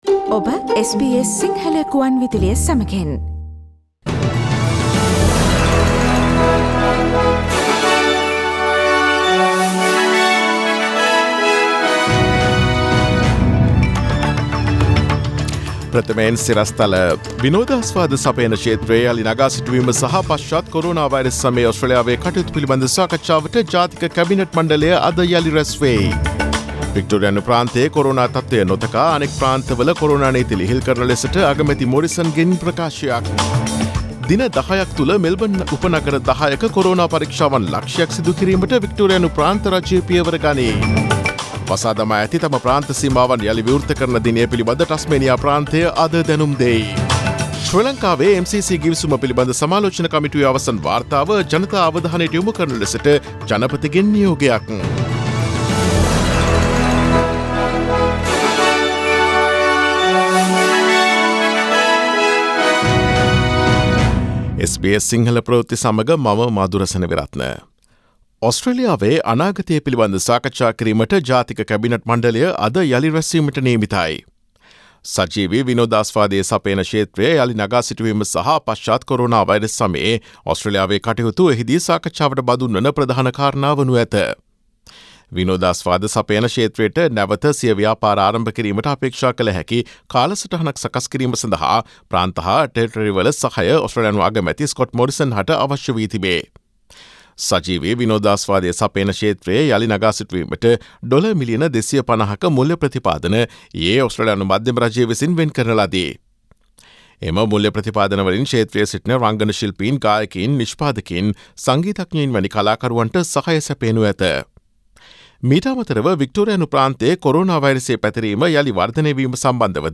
Daily News bulletin of SBS Sinhala Service: Friday 26 June 2020